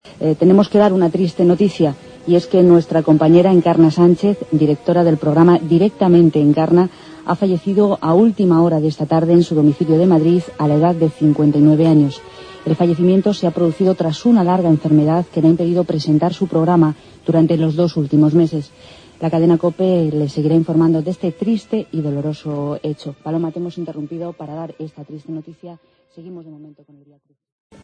La muerte de Encarna Sánchez en directo